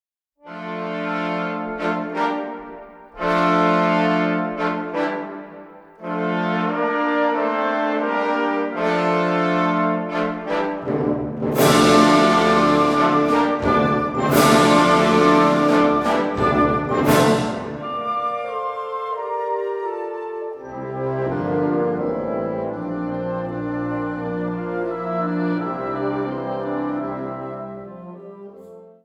Category Concert/wind/brass band
Subcategory modern popular band music
Instrumentation Ha (concert/wind band)